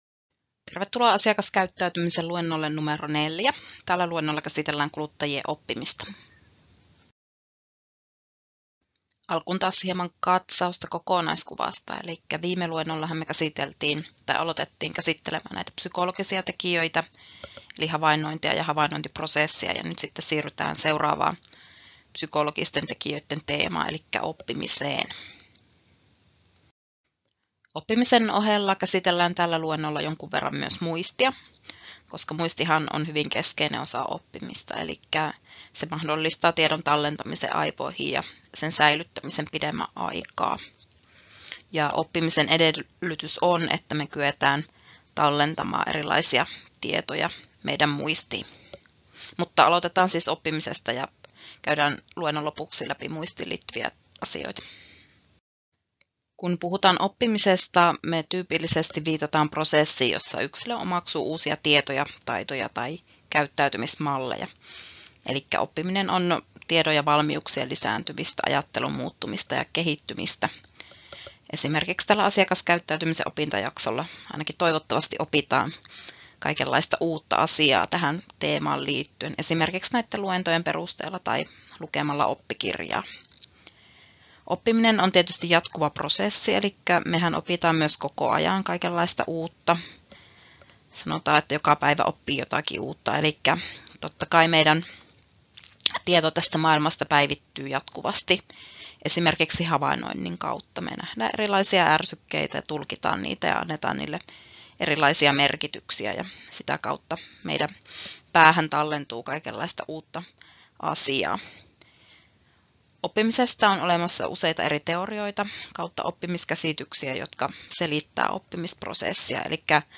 Luento 4: Psykologiset tekijät (oppiminen) — Moniviestin